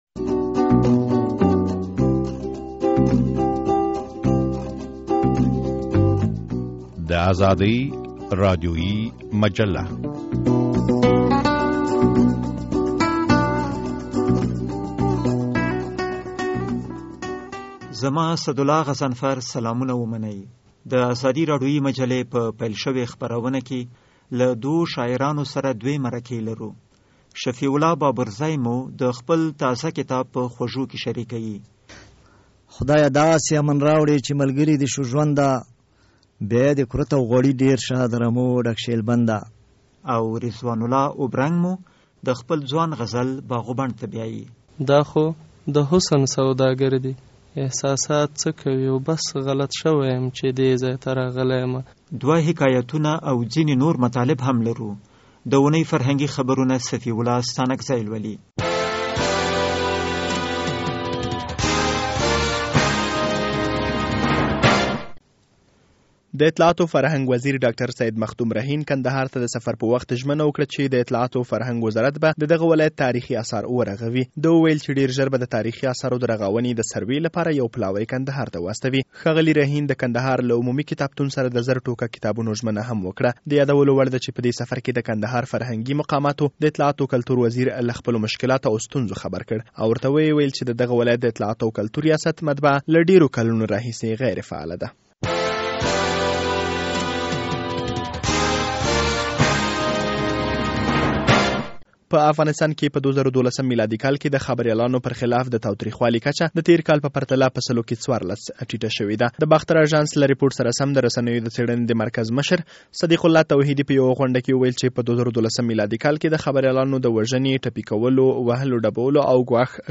بېلابیلې مرکې لرو